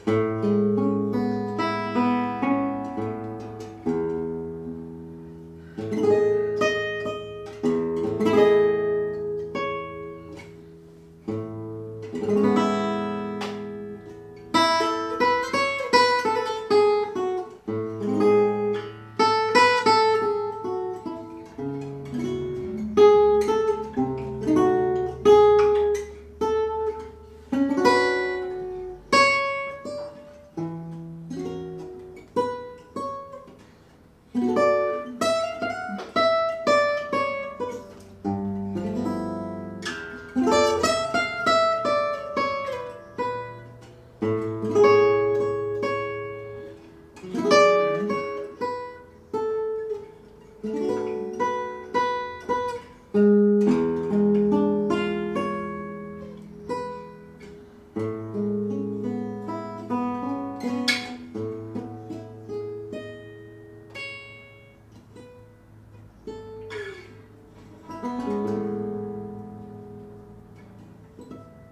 My 1960s Henri Miller sounds better for rhythm than solo work but I've been enjoying playing around with it a lot recently and so recorded a short solo clip.
Beautiful chord colours, and your old piece of wood has that much personality!